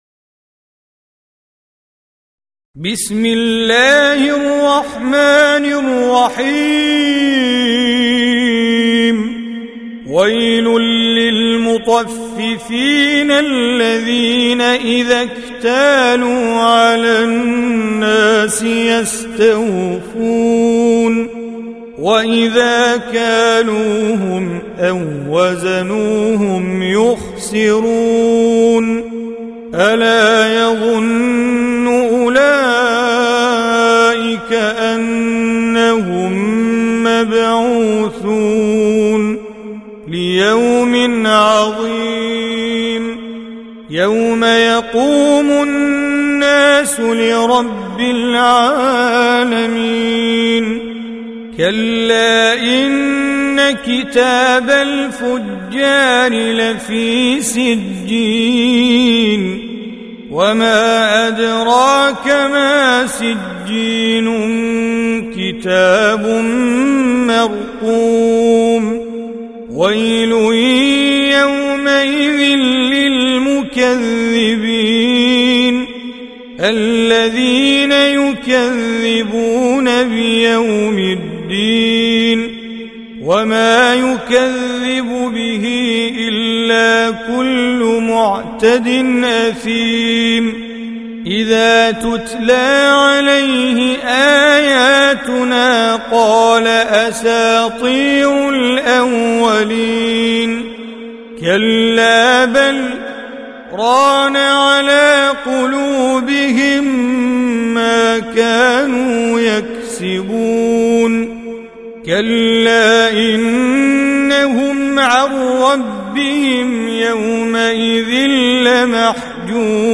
83. Surah Al-Mutaffif�n سورة المطفّفين Audio Quran Tajweed Recitation
حفص عن عاصم Hafs for Assem